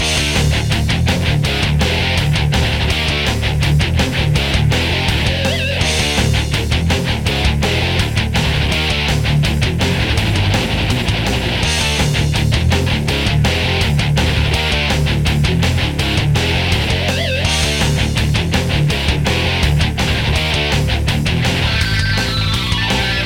Siin all on mitu erinevat audiofaili, ikka selleks, et oleks võimalik teema- ja rütmipartiid eraldi harjutada.
Kidrakäik − rütmi osa loop: